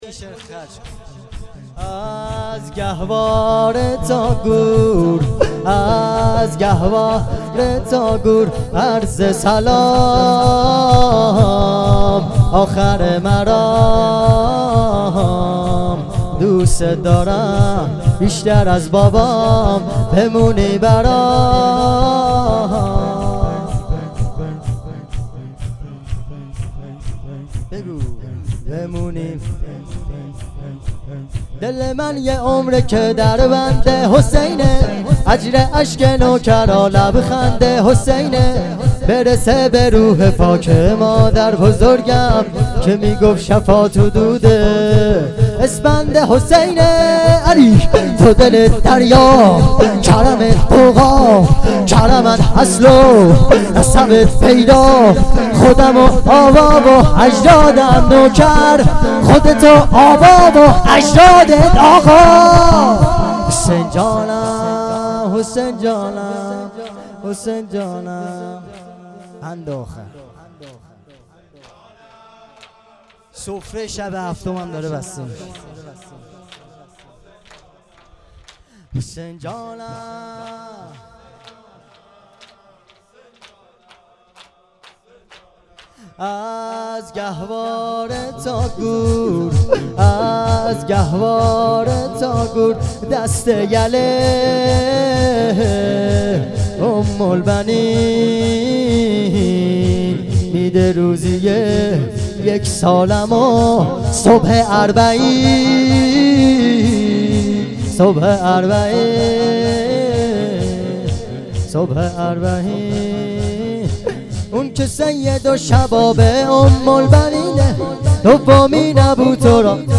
محرم شور